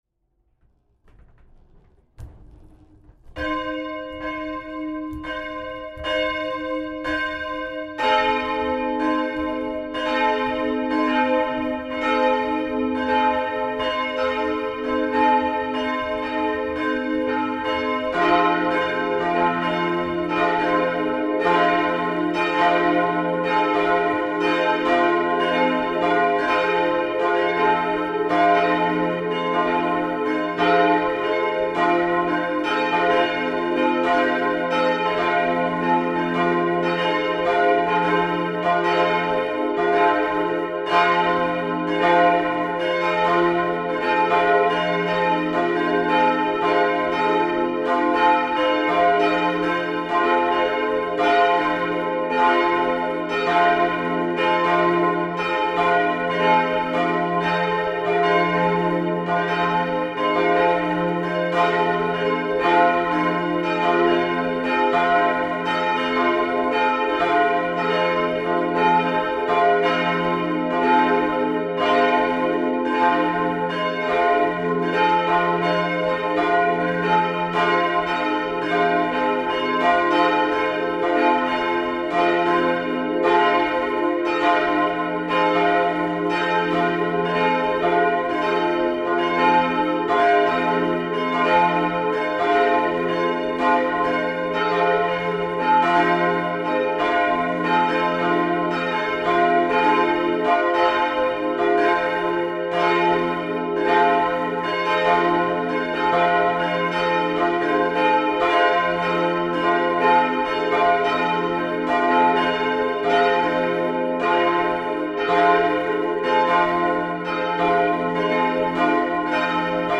Dann – nach einer kurzen Pause – läuten 5 Minuten lang alle drei Glocken zusammen den Sonntag ein.
Glocke Ton Durchmesser Gewicht Aufschrift Glocke I Totenglocke f' 126 cm 778 kg Der Tod ist verschlungen in den Sieg.
Herschweiler-Pettersheim-Prot.-Kirche-St.-Michael-Plenum-Ton.mp3